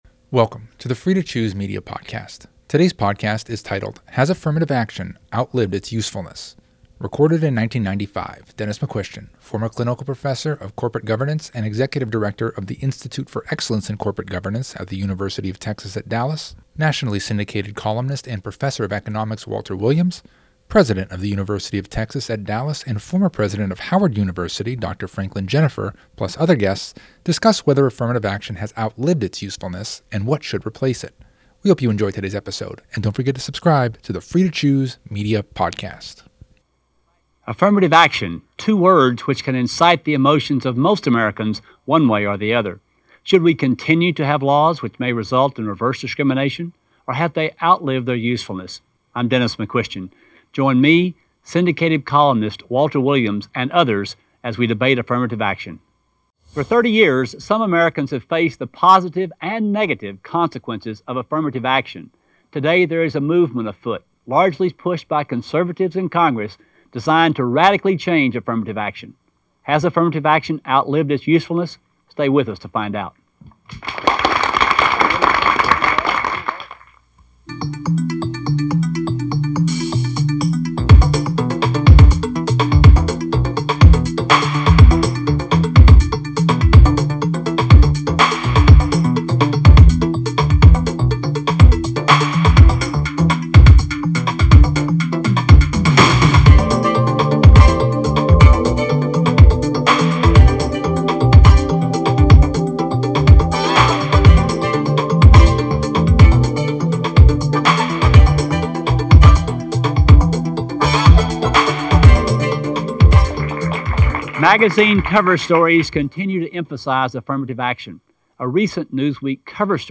plus other guests, discuss whether affirmative action has outlived its usefulness and what should replace it.